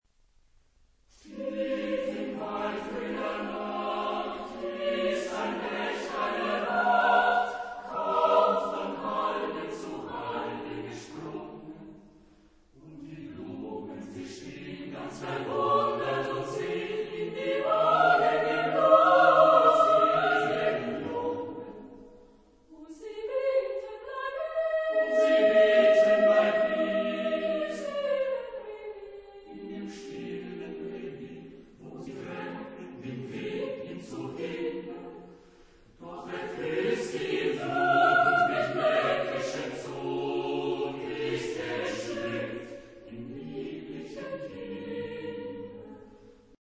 Editado por Carus-Verlag Stuttgart [Alemania] , 2001 ; in Weltliche Musik für Gemischten Chor a cappella (32 p.)
Género/Estilo/Forma: Profano ; Romántico
Carácter de la pieza : andantino
Tipo de formación coral: SATB  (4 voces Coro mixto )
Tonalidad : la bemol mayor